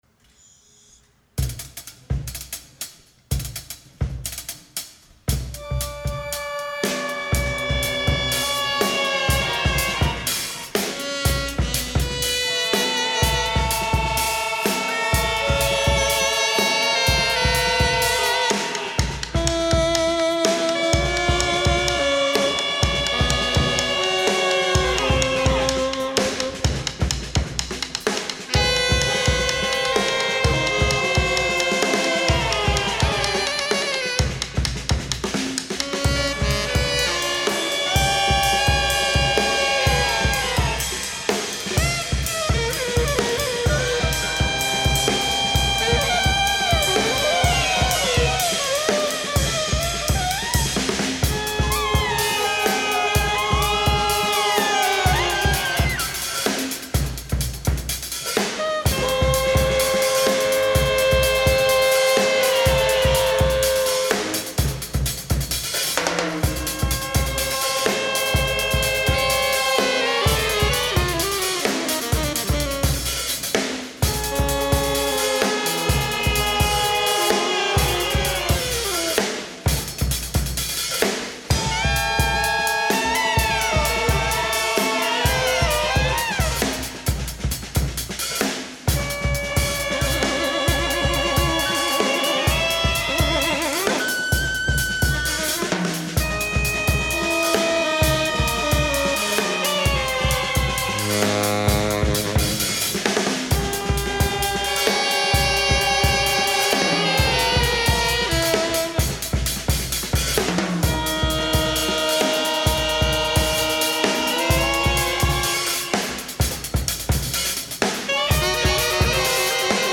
Recorded live at the 39th Street loft in Brooklyn
drums
alto saxophone, fx
tenor saxophone
Stereo (Metric Halo / Pro Tools)